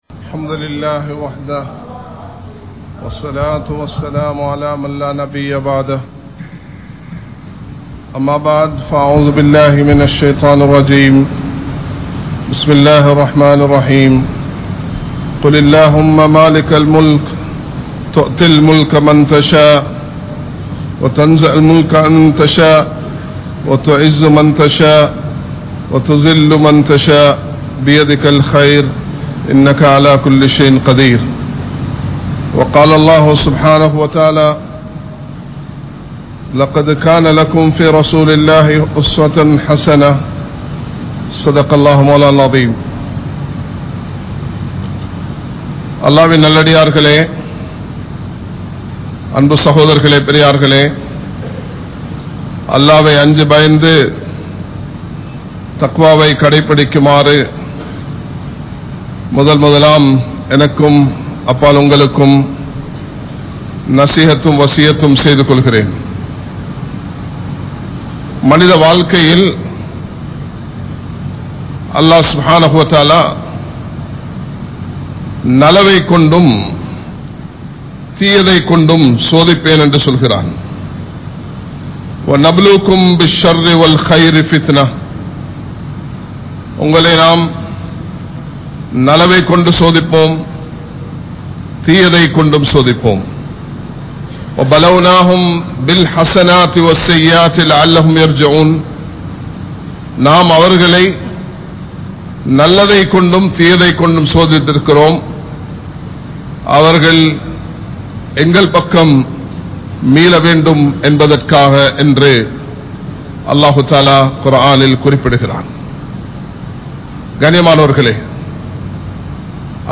Aniyaayam Alinthu Poai Vidum (அநியாயம் அழிந்து போய்விடும் ) | Audio Bayans | All Ceylon Muslim Youth Community | Addalaichenai
Kollupitty Jumua Masjith